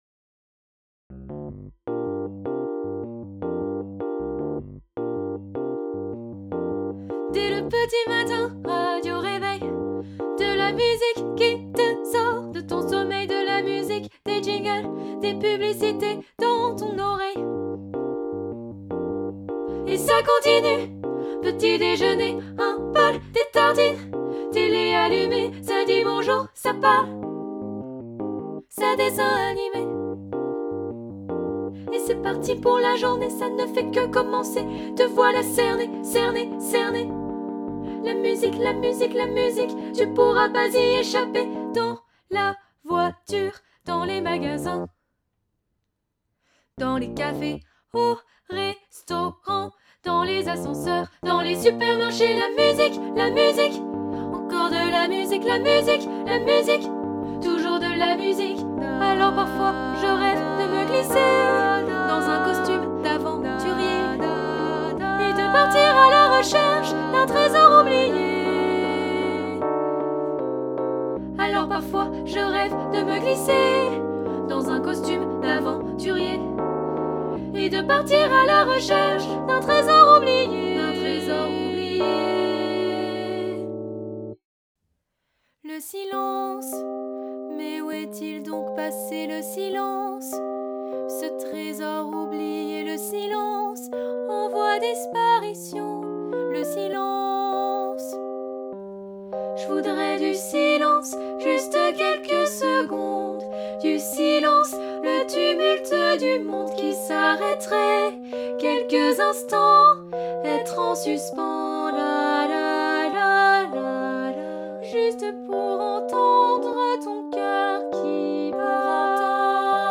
Genre :  Chanson
Style :  Avec accompagnement
Effectif :  PolyphonieVoix égales
Enregistrement piano et voix